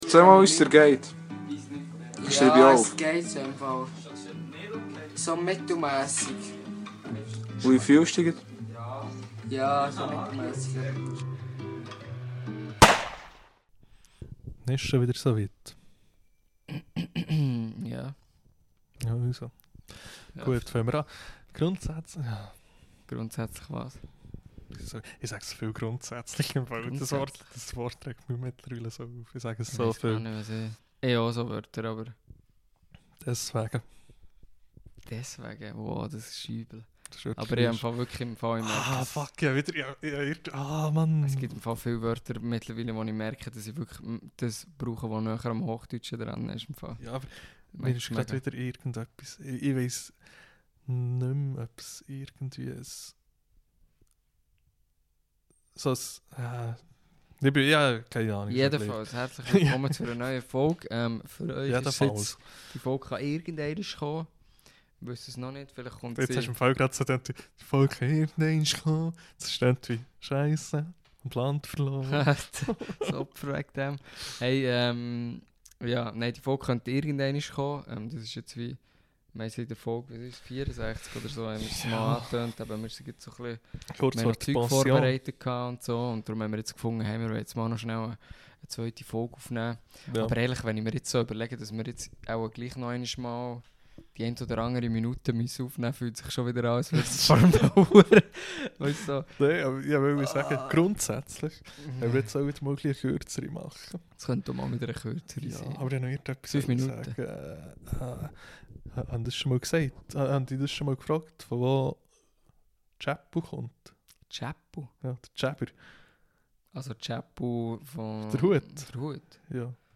Woher chunnt ds Wort Tschäppu? Di Wuche heimer mau e vorproduzierti Foug am Start, dases nid wider zu irgendwelche Lücke chunnt.